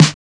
707 SN SOFT.wav